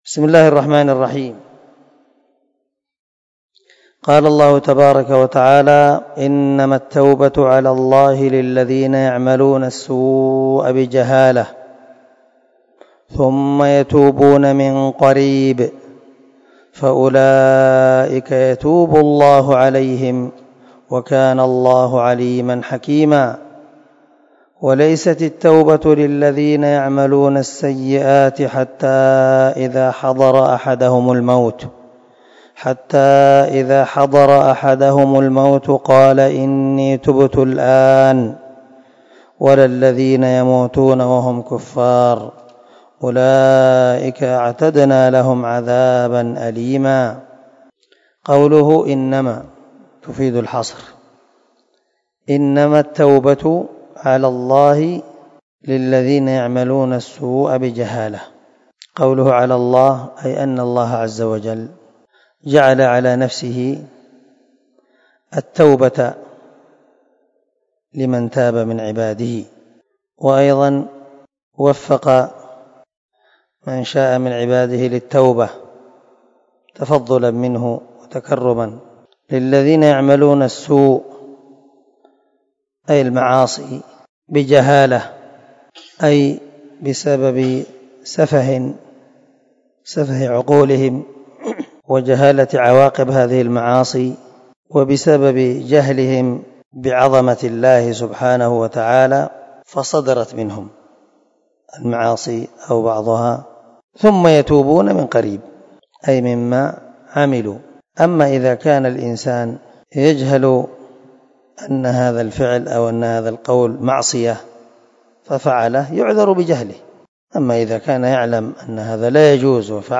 249الدرس 17 تفسير آية ( 17 – 18 ) من سورة النساء من تفسير القران الكريم مع قراءة لتفسير السعدي